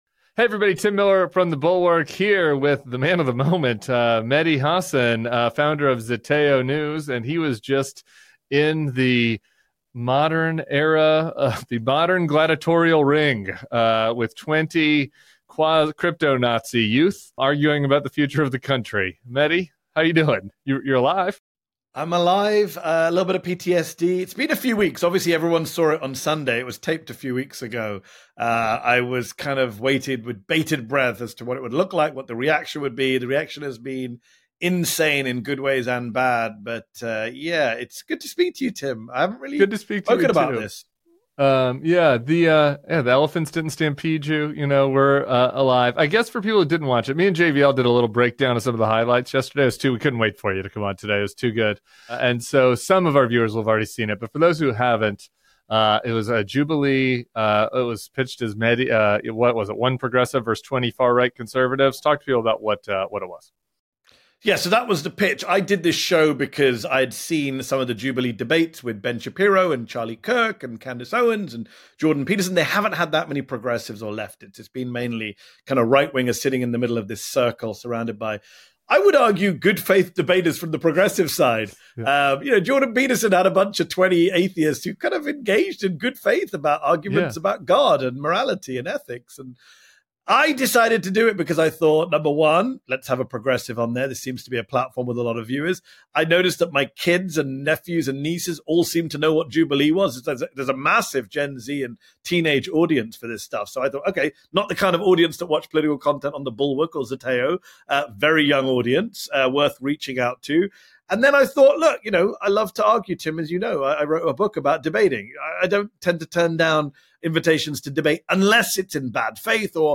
Mehdi Hasan joins Tim Miller to unpack his shocking Jubilee debate experience, where he faced off against 20 young members of the far right—some whom were openly fascist—and was left speechless.